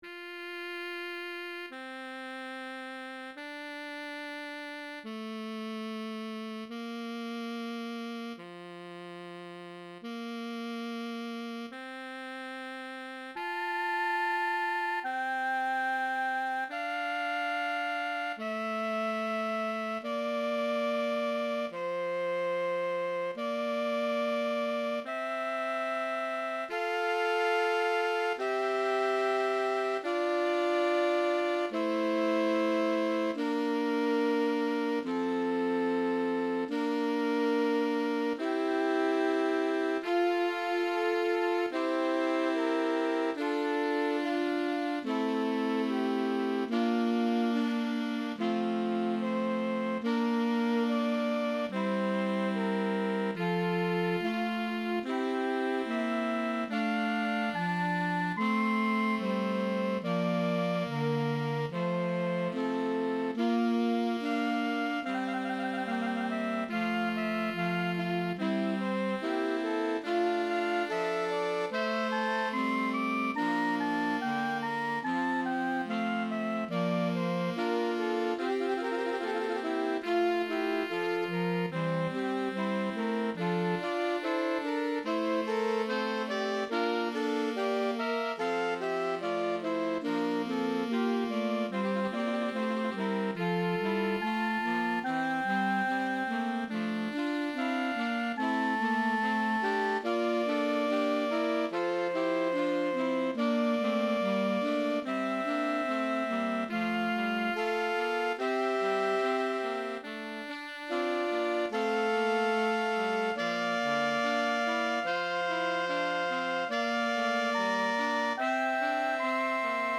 Voicing: Saxophone